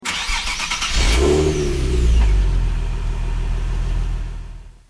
Home gmod sound vehicles tdmcars golfvr6mk3
enginestart.mp3